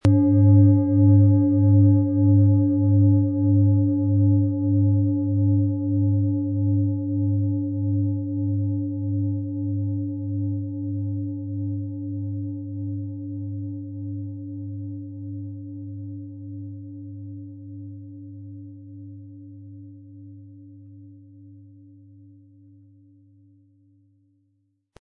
Tibetische Universal-Klangschale, Ø 23,3 cm, 1200-1300 Gramm, mit Klöppel
Wir haben ebendiese Klangschale beim Aufnehmen angespielt und das persönliche Empfinden, dass sie alle Körperregionen gleich deutlich zum Schwingen bringt.
Im Audio-Player - Jetzt reinhören hören Sie genau den Original-Klang der angebotenen Schale. Wir haben versucht den Ton so authentisch wie machbar aufzunehmen, damit Sie gut wahrnehmen können, wie die Klangschale klingen wird.
Durch die traditionsreiche Fertigung hat die Schale vielmehr diesen kraftvollen Ton und das tiefe, innere Berühren der traditionellen Handarbeit
MaterialBronze